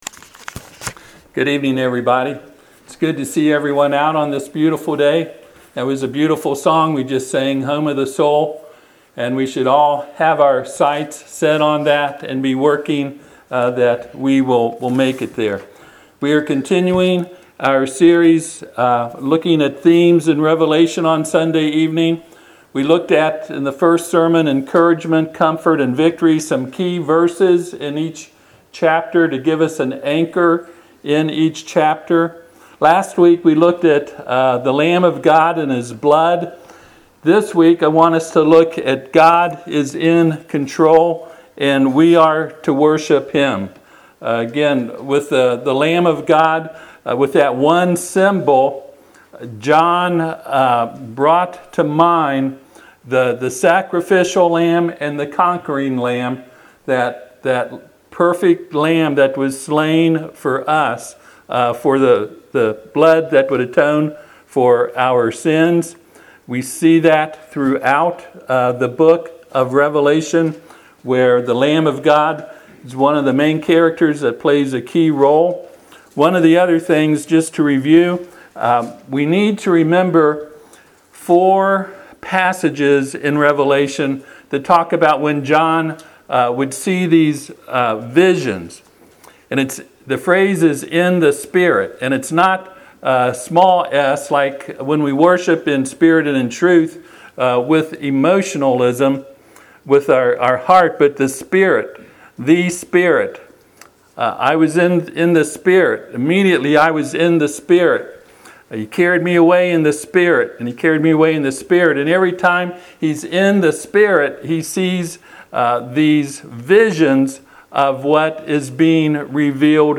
Revelation Passage: Revelation chapter 4 Service Type: Sunday PM Topics